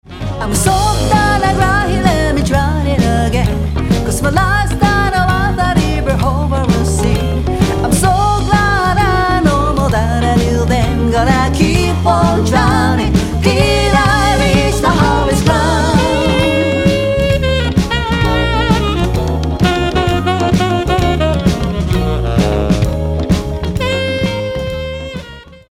円熟のヴォーカルが描く、ソウル・ジャズ。
Vocal/Chorus
Drums